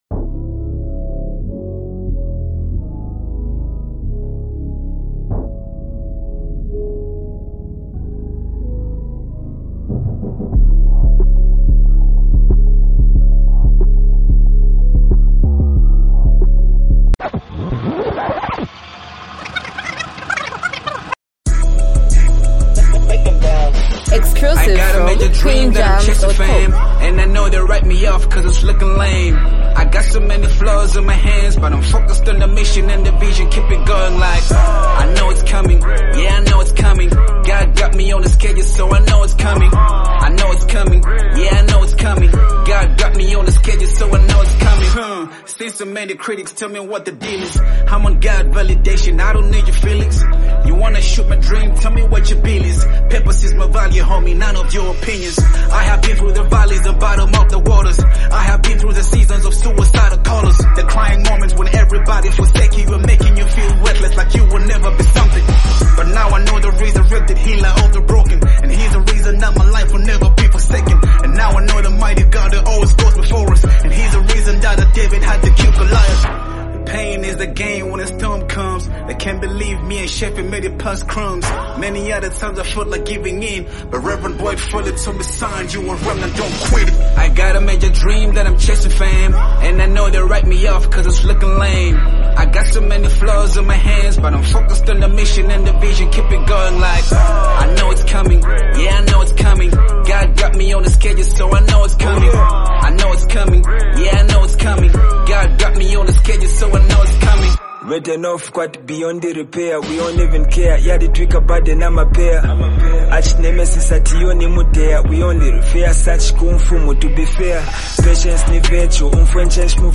motivational hip-hop track
The song carries an uplifting tone
sharp rap delivery